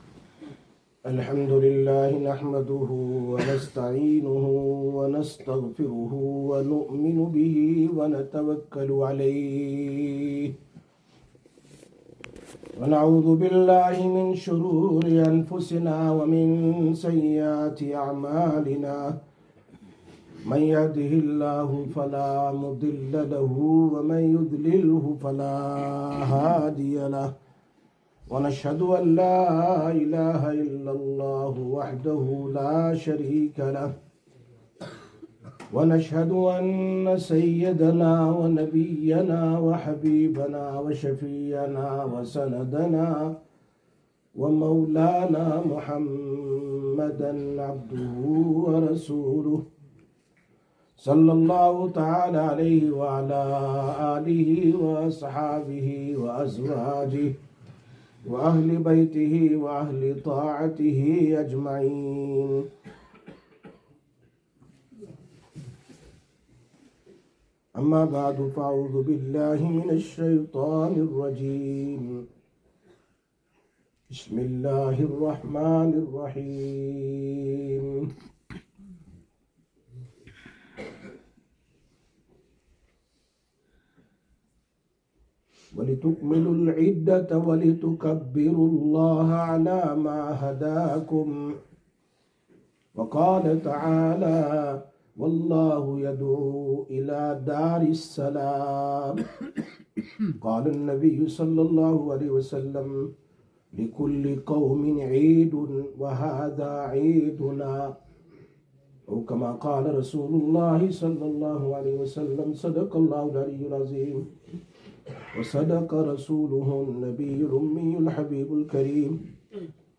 20/03/2026 Eid ul Fitr Bayaan